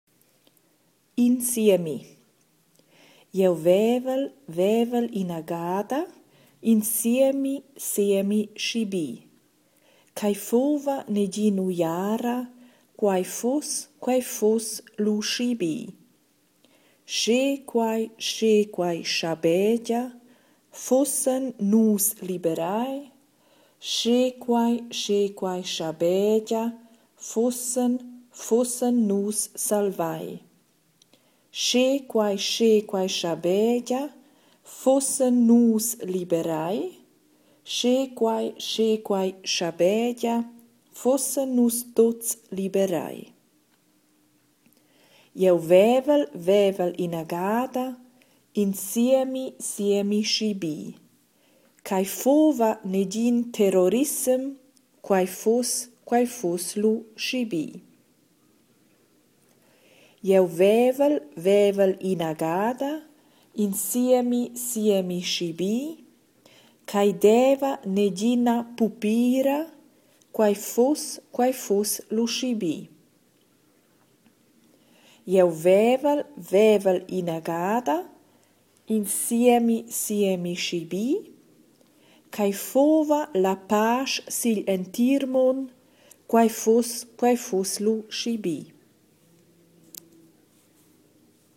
32 In siemi // Aussprache